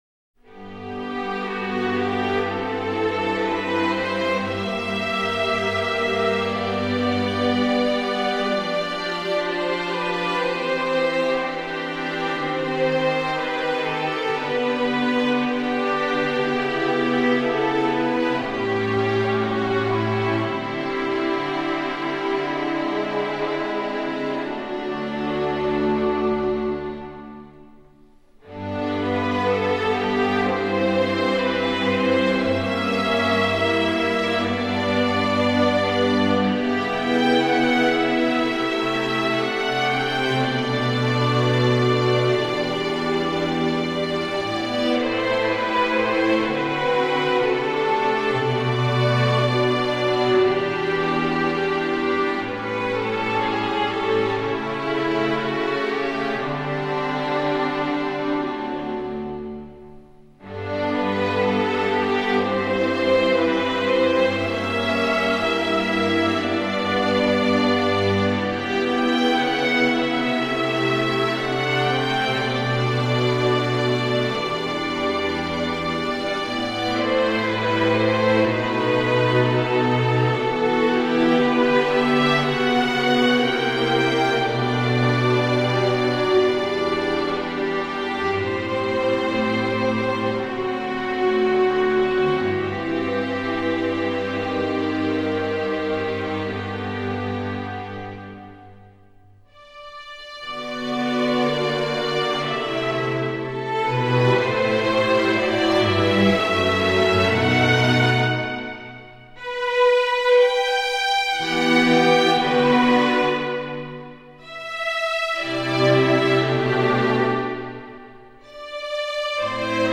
让人感受电视剧中自然的魅力。